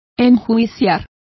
Complete with pronunciation of the translation of prosecute.